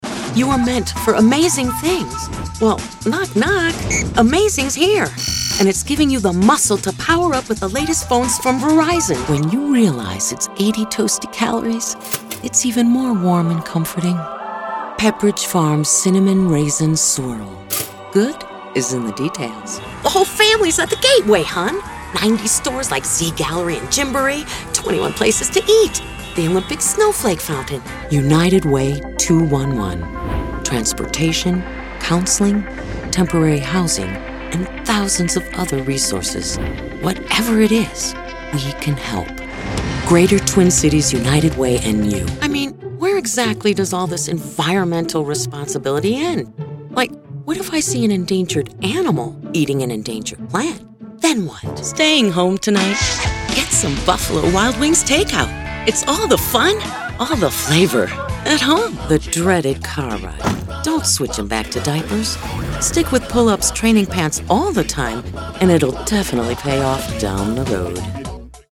female natural character voice, rich, deep, smokey